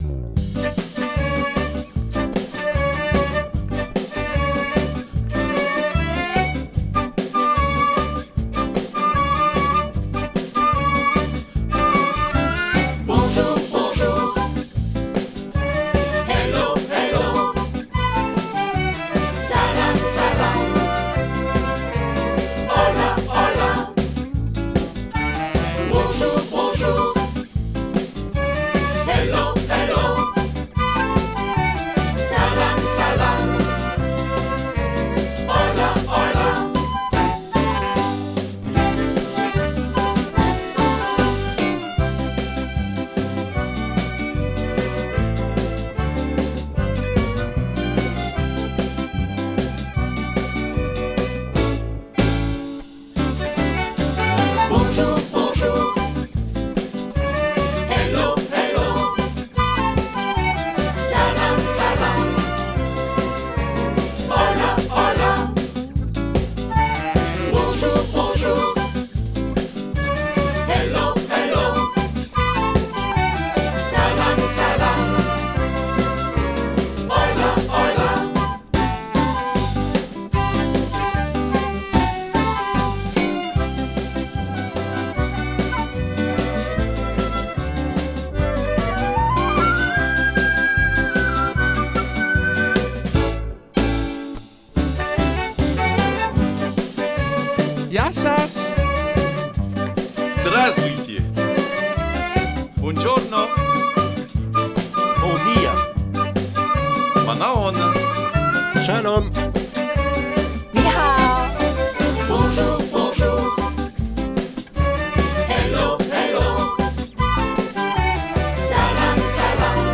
Versions instrumentales téléchargeables